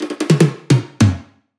VR_drum_fill_tomroll_150.wav